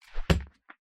На этой странице собраны реалистичные звуки выстрелов из рогатки разными снарядами: от камней до металлических шариков.
Звук выстрела из рогатки точно попадает в цель